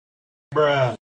Sound Buttons: Sound Buttons View : Bruh Sound
bruh_sound_1.mp3